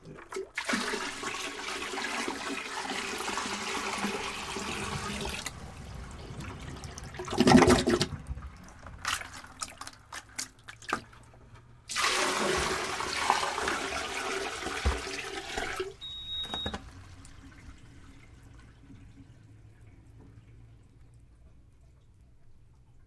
random » water run through water pipe basement clean hiss and gurgle1 short
描述：water run through water pipe basement clean hiss and gurgle1 short.flac
标签： gurgle basement clean through water hiss run pipe
声道立体声